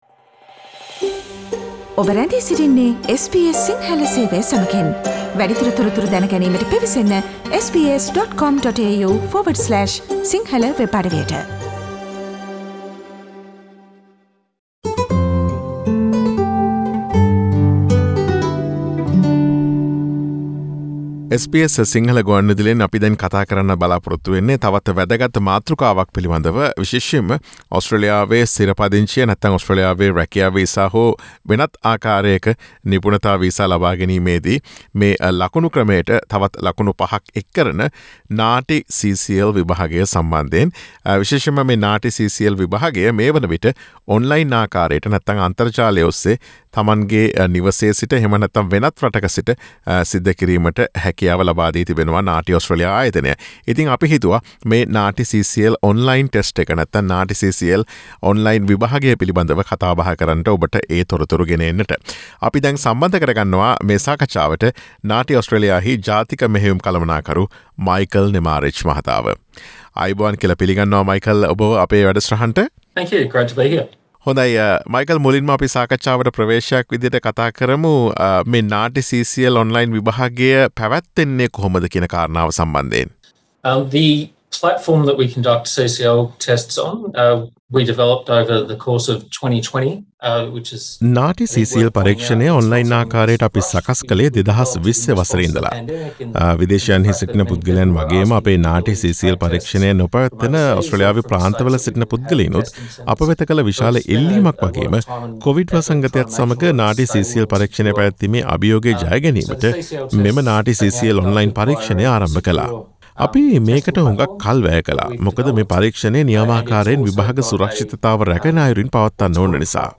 ඔස්ට්‍රේලියාවේ ස්ථිර පදිංචියට මෙන්ම ඕනෑම Skilled වීසා බලපත්‍රයකට ලකුණු 5 ක් පිරිනමන NAATI CCL Online විභාගය පිළිබඳ SBS සිංහල ගුවන් විදුලිය සිදුකළ සාකච්ඡාවට සවන් දෙන්න.